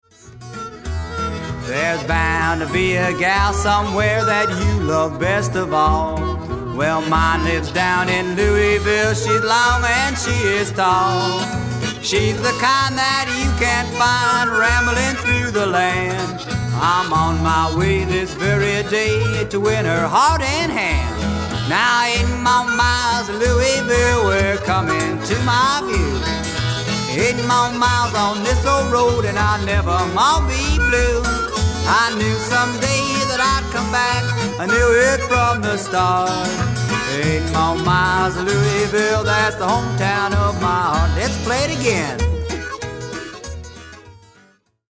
JUG MUSIC / AMERICAN ROOTS MUSIC / BLUES